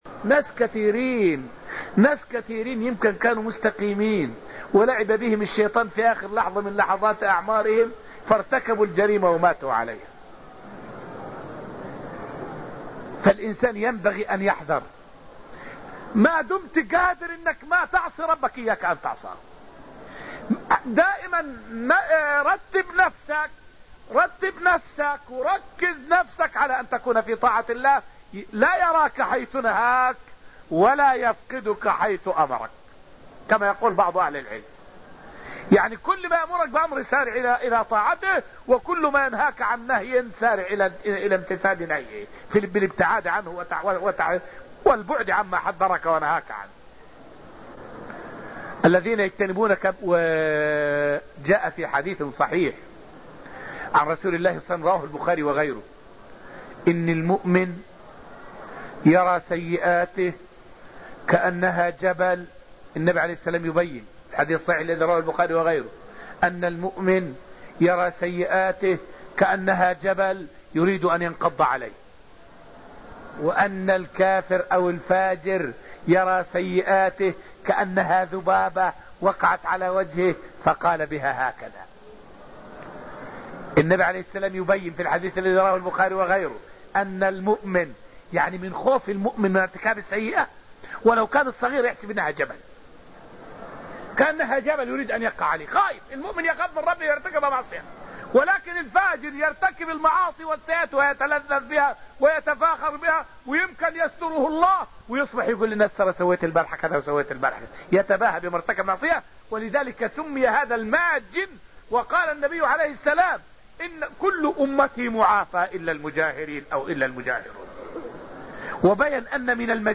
فائدة من الدرس الحادي عشر من دروس تفسير سورة النجم والتي ألقيت في المسجد النبوي الشريف حول التحذير من معصية الله والحث على امتثال أوامره.